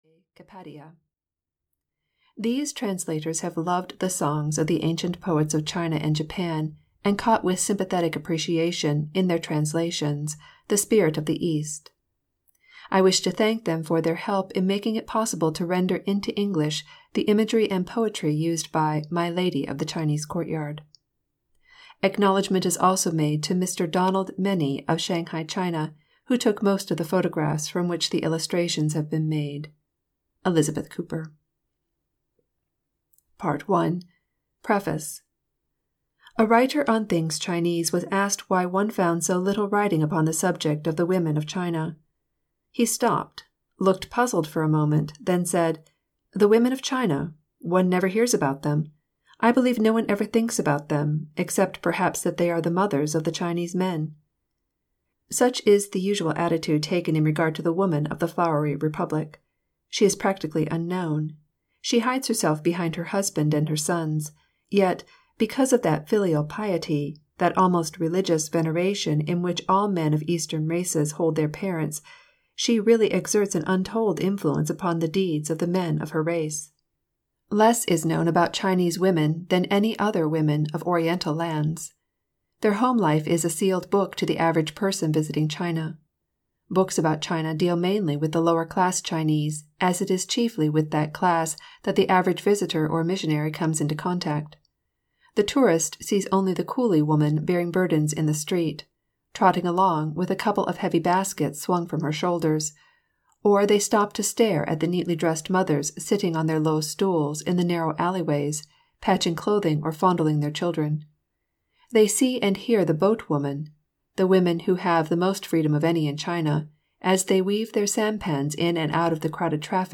Audio knihaMy Lady of the Chinese Courtyard (EN)
Ukázka z knihy